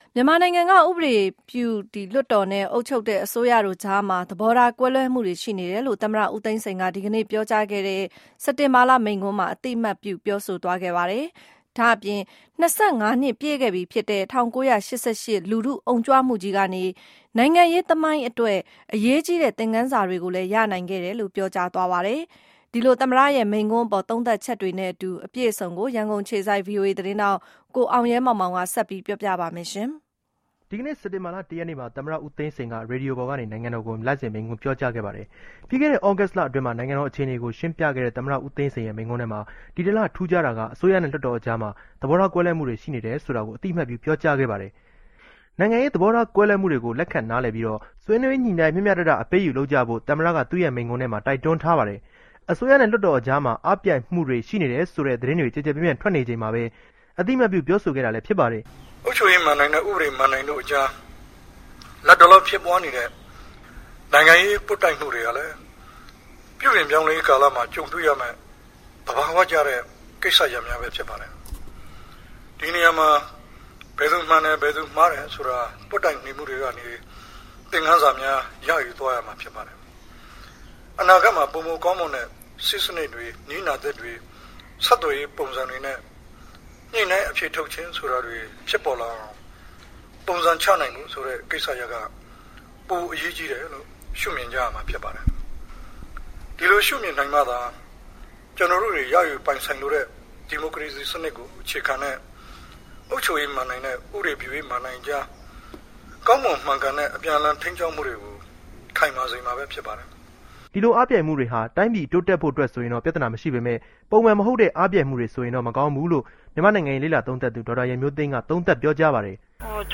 U Thein Sein Monthly Speech